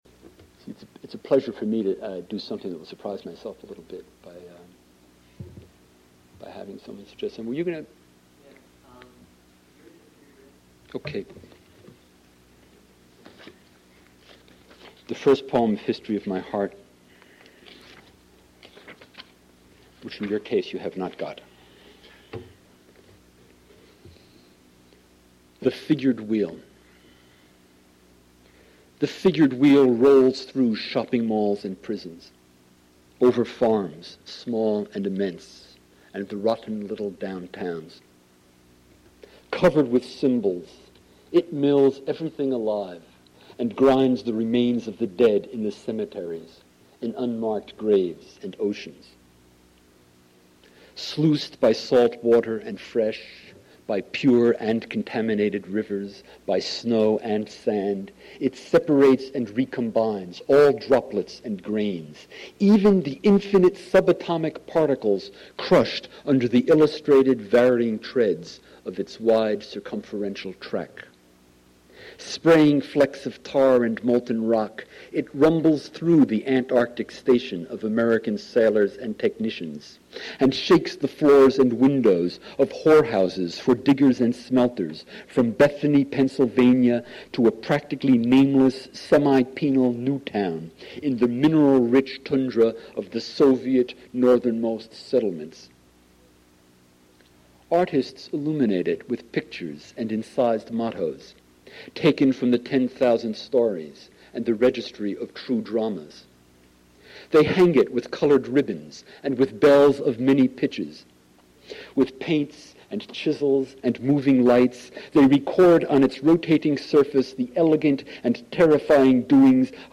Spring Reading Series
Modern Languages Auditorium